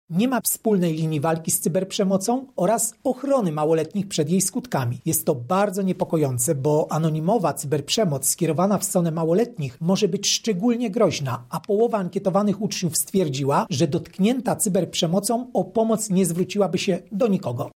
– Resorty cyfryzacji i edukacji narodowej oraz policja nie określiły właściwie skali zagrożenia – mówi Krzysztof Kwiatkowski, prezes Najwyższej Izby Kontroli.